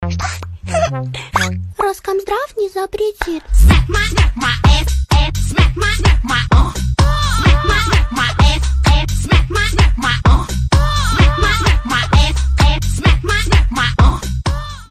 • Качество: 320, Stereo
веселые
Mashup
ремиксы